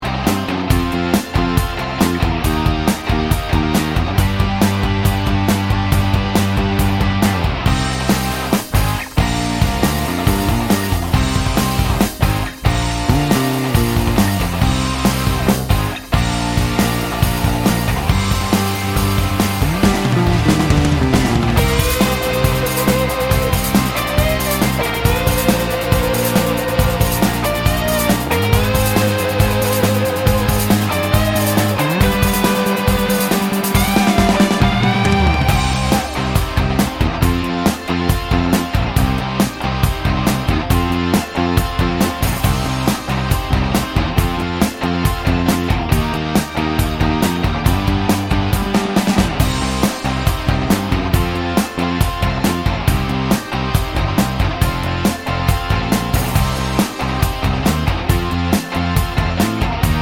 no Backing Vocals Rock 4:18 Buy £1.50